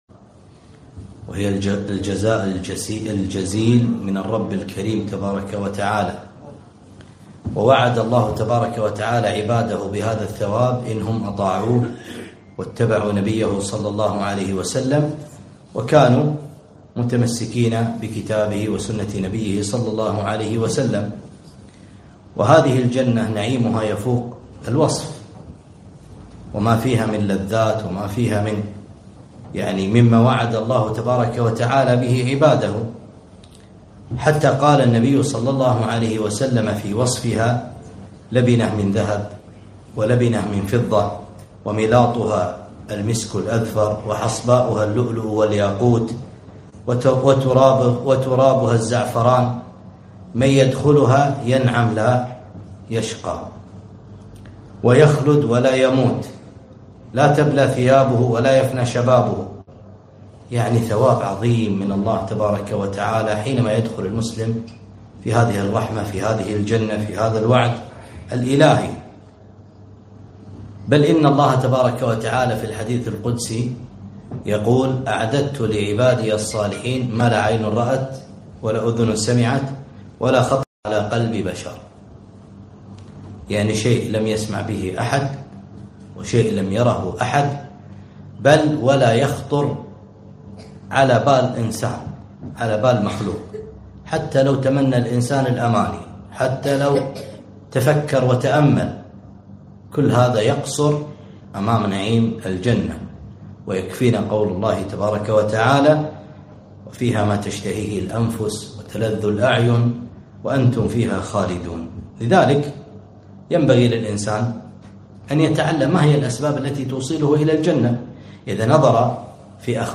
محاضرة - من أخبار أهل الجنة - دروس الكويت